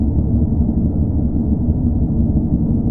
shipThrum.wav